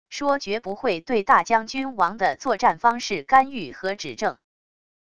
说绝不会对大将军王的作战方式干预和指正wav音频